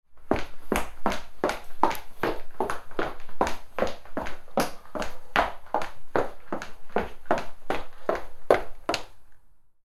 Human Sounds / Sound Effects 5 Dec, 2025 Fast Footsteps On Hard Wooden Floor Sound Effect Read more & Download...
Fast-footsteps-on-hard-wooden-floor-sound-effect.mp3